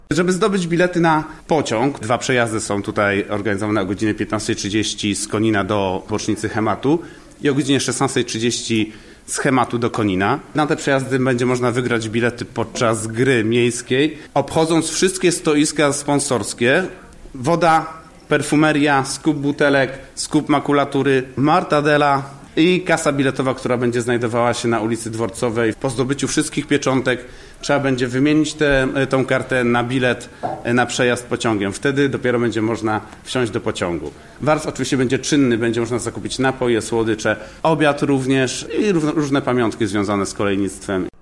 „Po mieście będzie jeździć autobus jak z "Misia", za miasto można się będzie wybrać old schoolowym pociągiem, ale na bilet kolejowy trzeba będzie zapracować” - mówi jeden z organizatorów